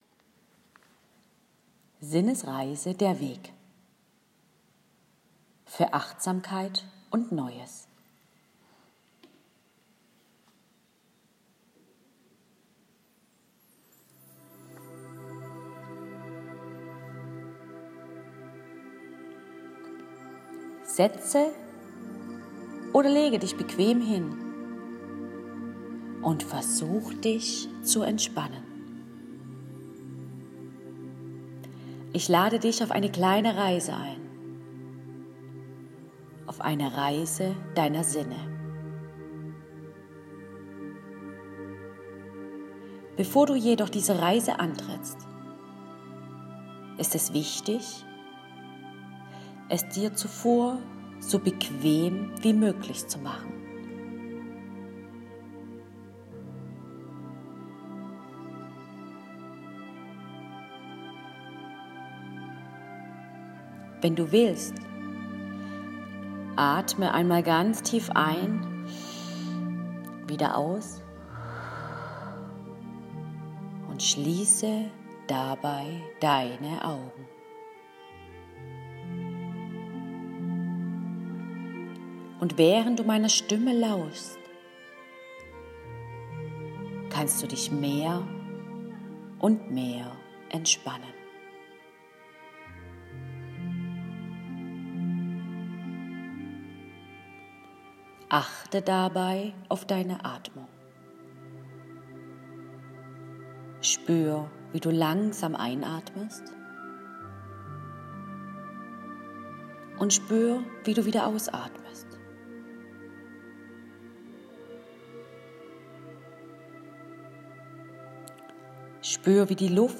Sinnesreise zum Hören